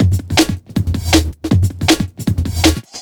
JUNGLE5-R.wav